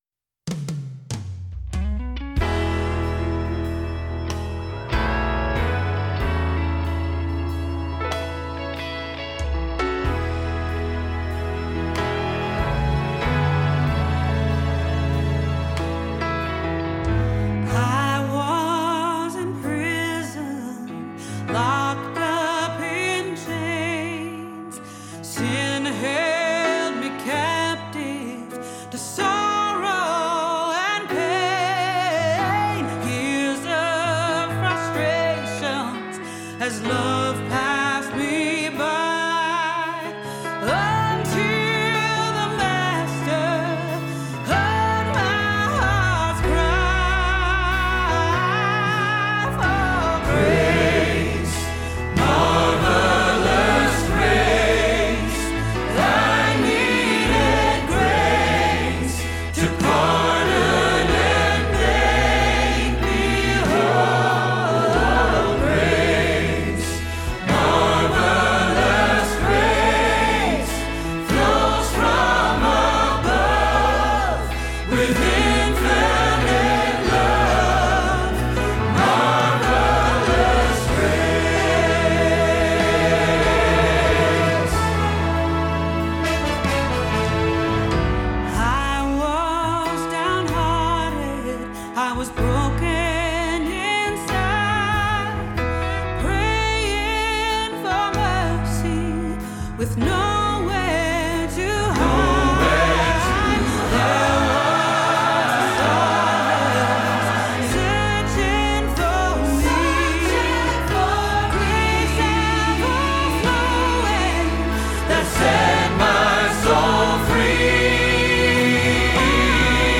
02-Grace-with-Grace-Greater-Than-Our-Sin-Bass-Rehearsal-Track.mp3